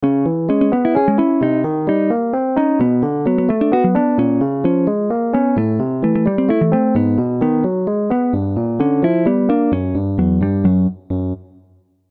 Played Dead Space, and something started humming in the back of my head, mysterious, alien-like, mistery tune.